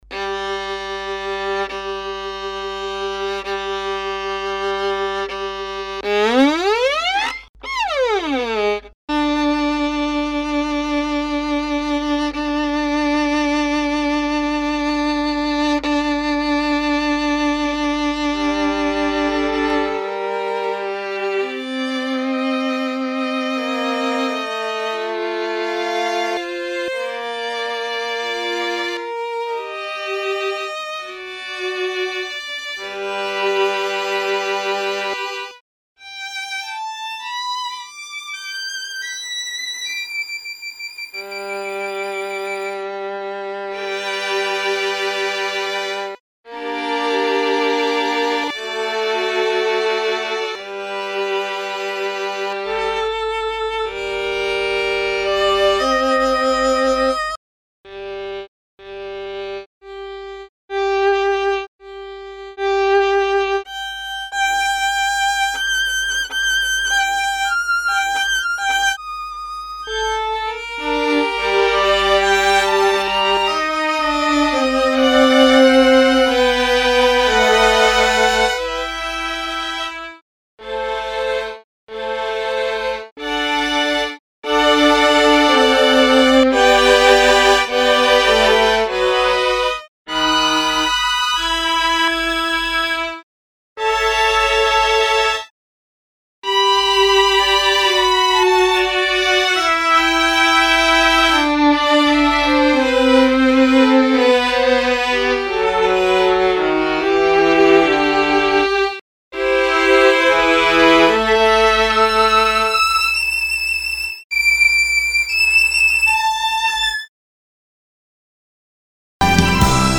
dq-altro provino violini.mp3